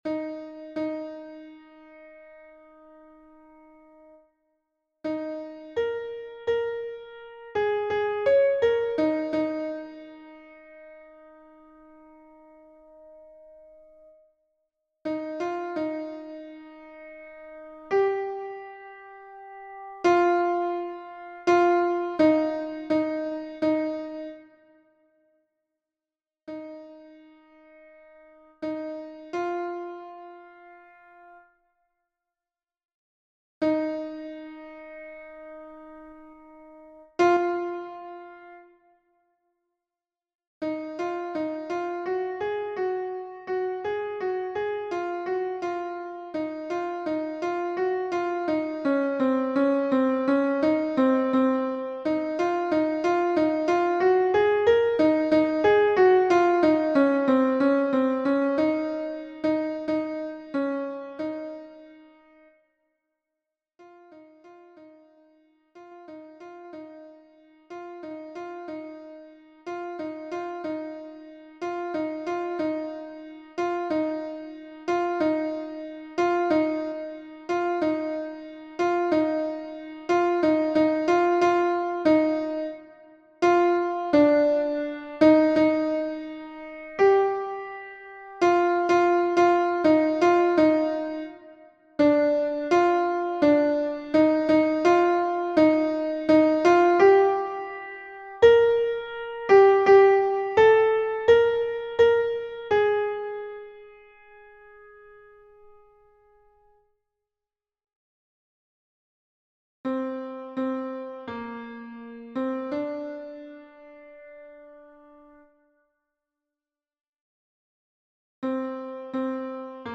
MP3 version piano
Alto 1